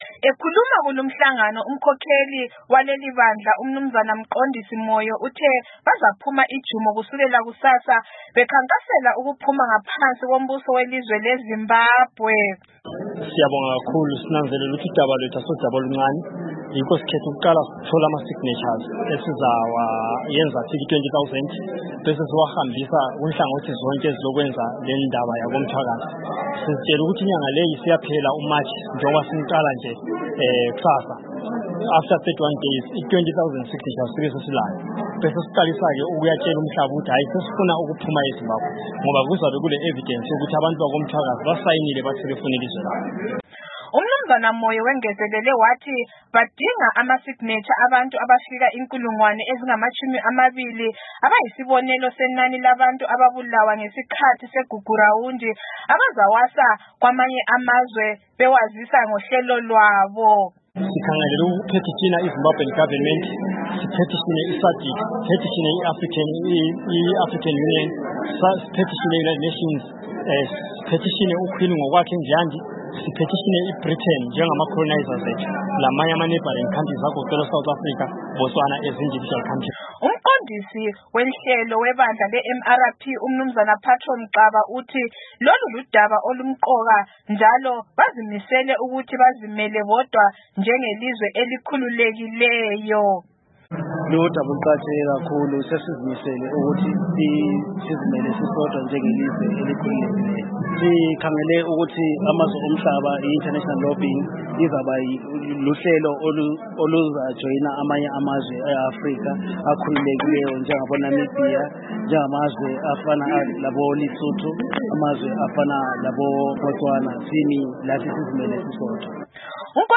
Ibandla leMthwakazi Republic Party liqhube umhlangano walo omkhulu we rally eTshabalala Hall ngoMgwibelo, lapho elitshele abalandeli balo ukuthi lizakwazisa amazwe omhlaba kusukela kusasa lisebenzisa i-petition, ukuthi alisafuni ukubalelwa ngaphansi kombuso weZimbabwe, ekuqhubekeni komkhankaso wabo wokuthi elikaMthwakazi kufanele lizimelelodwa njengelizwe.